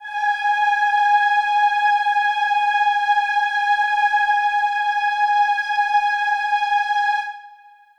Choir Piano
G#5.wav